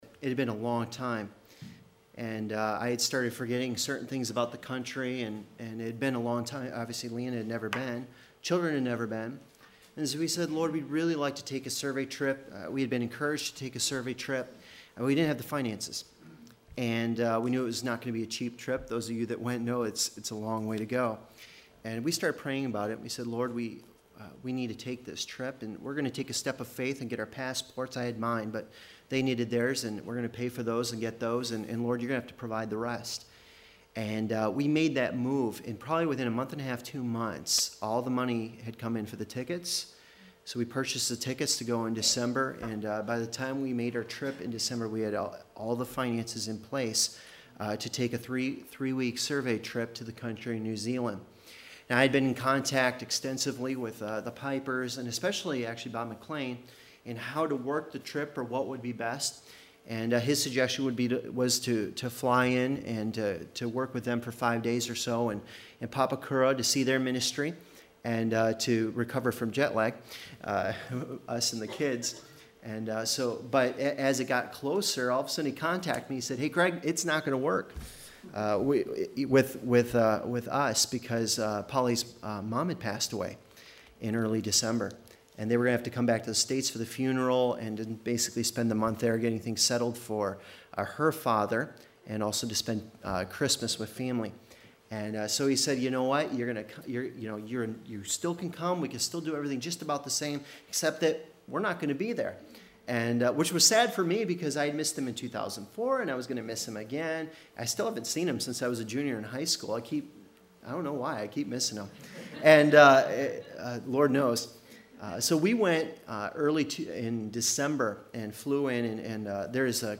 All sermons available in mp3 format